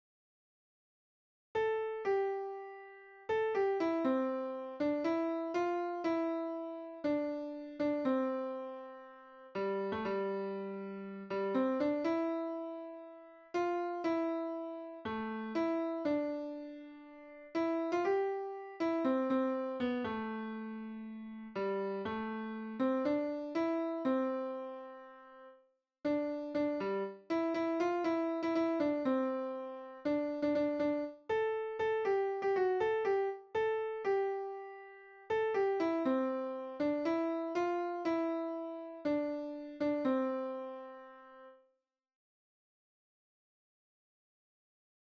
���ϡ������OB����¼����Ϻ����(1967����´)��������(��¼��Ϻ����)�ȹ�����ΤǤ��� ����(pdf) �ԥ��β����ˤ�����(mp3) �ܥ����Ⱦ�(mp3) ��Synthesizer V Studio Basic�פǲ����饤�֥��֥���֡פ�Lite�Ǥ����� �����ǡ���(MIDI) ������ �� �� (�� ��澰(1966ǯ´)���׿�)
ouka.chirishiku.oka.piano.mp3